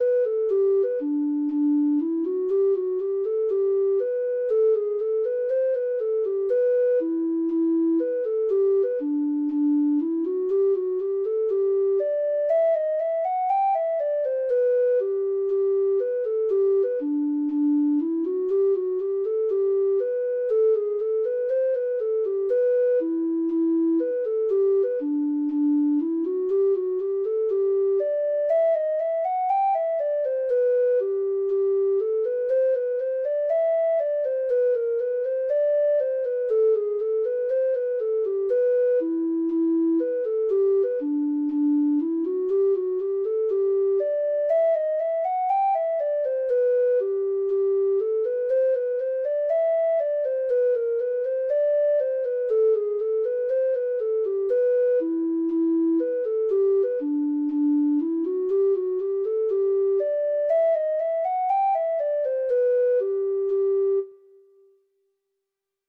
Traditional Trad. The Fearless Boys (Irish Folk Song) (Ireland) Treble Clef Instrument version
Free Sheet music for Treble Clef Instrument
Traditional Music of unknown author.